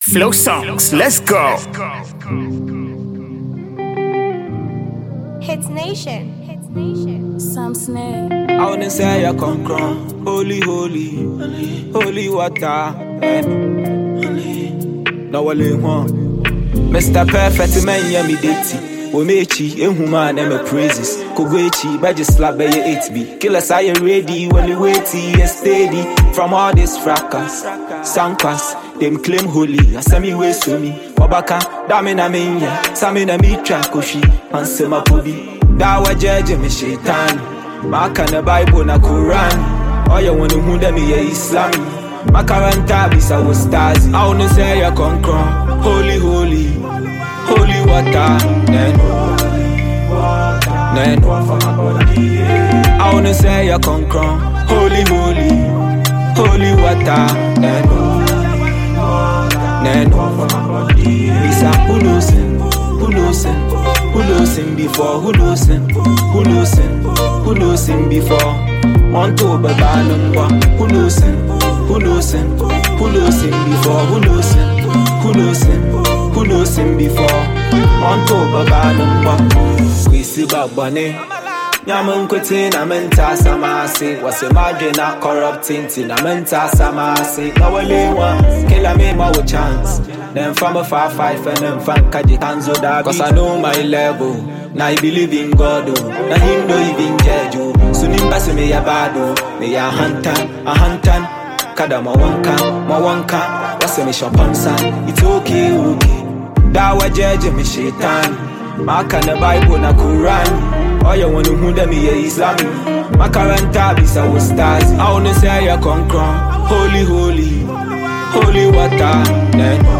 This soulful track
powerful vocals and emotional delivery
With its catchy melody and heartfelt lyrics